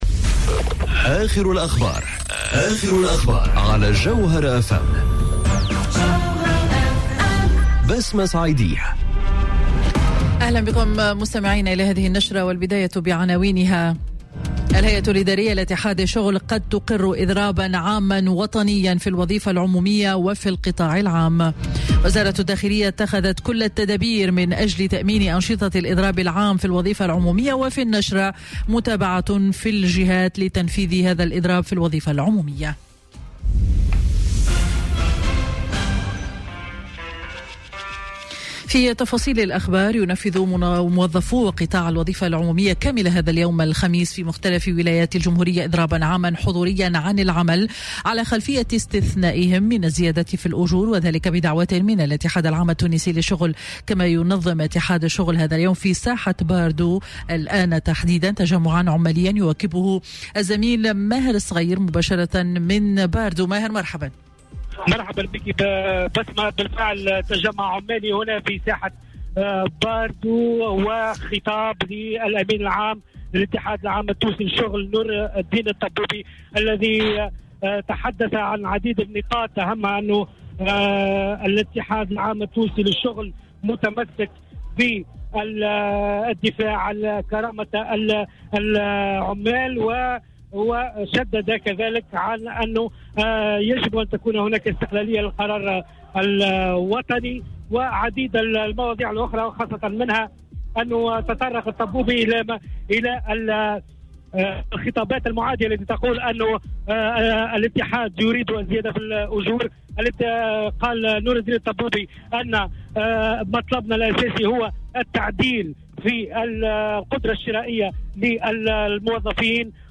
نشرة أخبار منتصف النهار ليوم الخميس 22 نوفمبر 2018